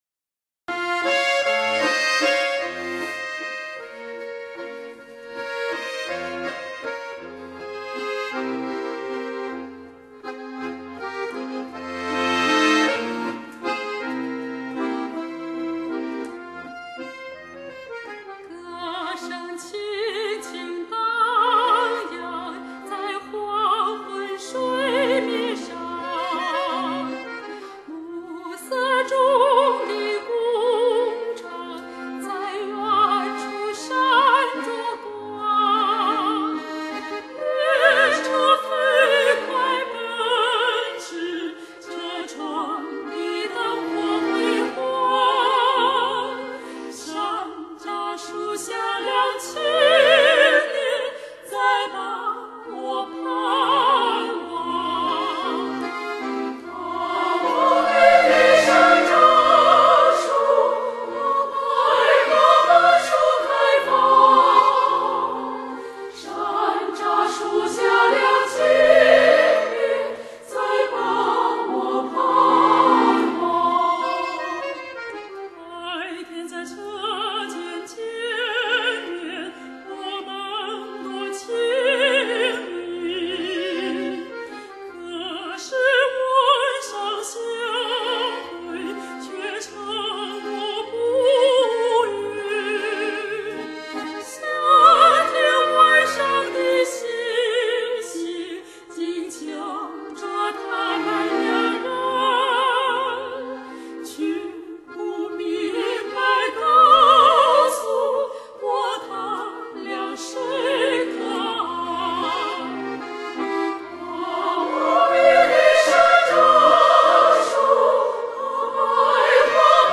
首创--国际WIZOR+SRS 3D音效环绕天碟
通音箱也能产生出真正的360度"真实现场感"声场！
女二声部 合唱